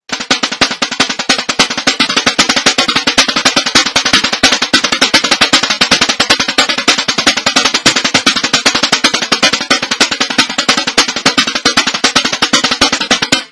.המקבילה הברזילאית לתוף הסנר
caixa.wma